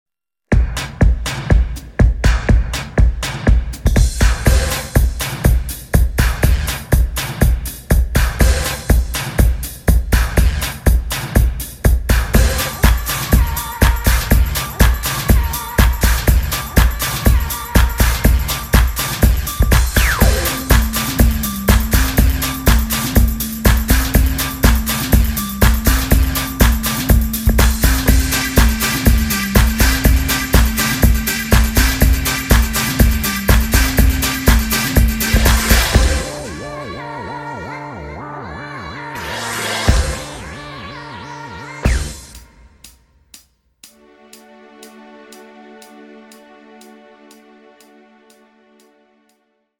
(sans choeurs)